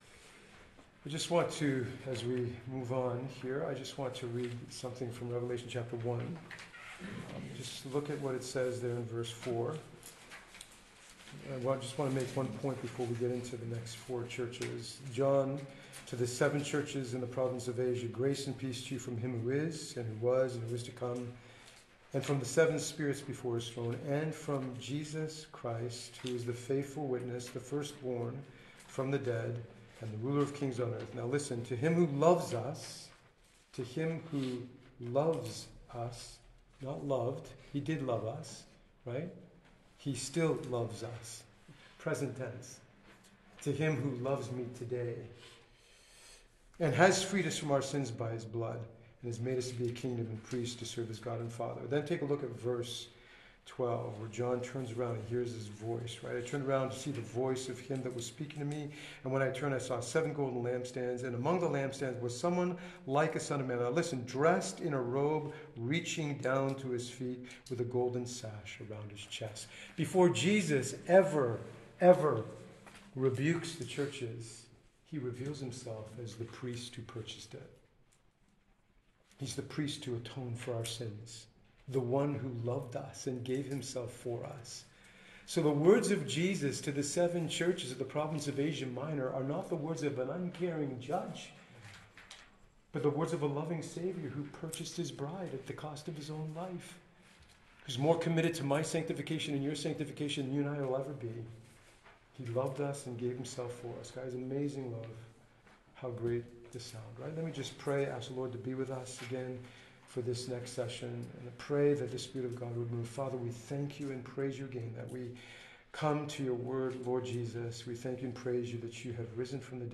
Western Ontario Men’s Conference 2023